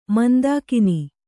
♪ mandākini